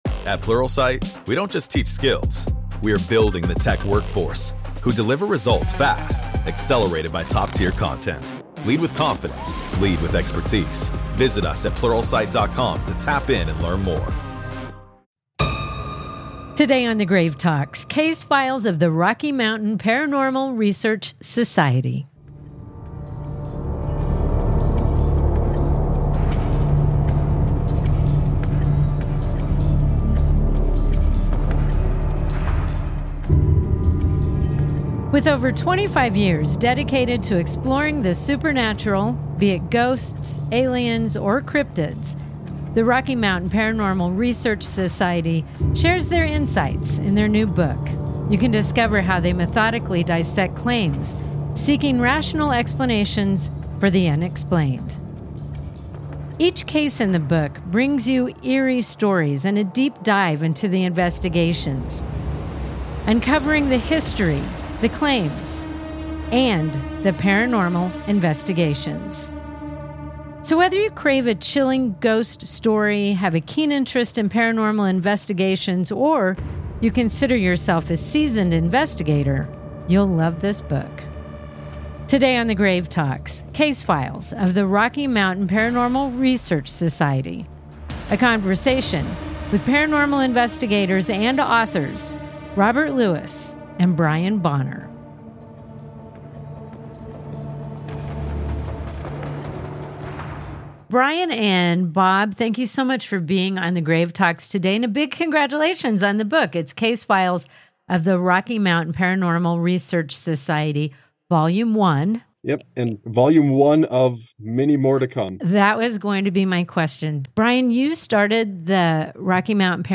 Whether you believe in ghosts, aliens, or monsters (or all three), this is one interview that will make you question everything you thought you knew about the unknown.